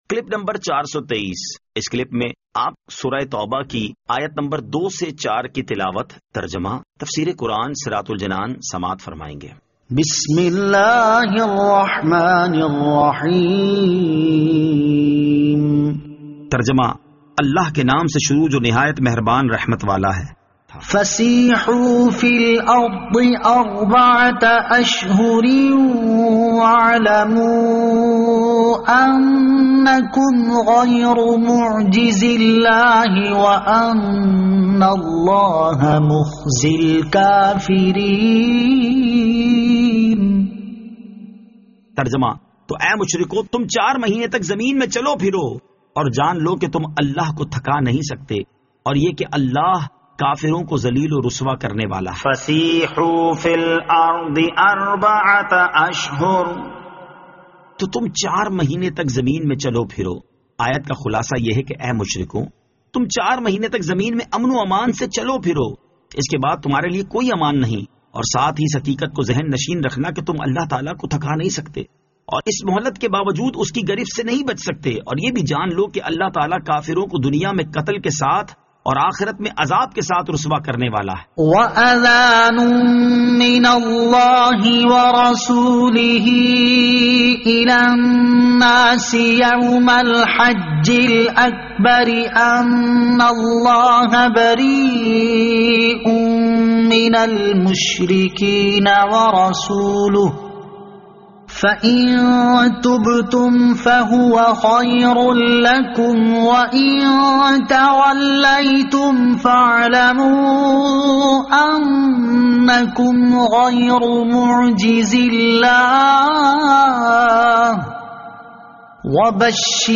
Surah At-Tawbah Ayat 02 To 04 Tilawat , Tarjama , Tafseer